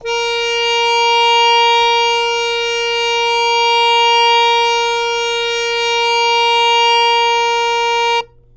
samples / harmonium / As4.wav
As4.wav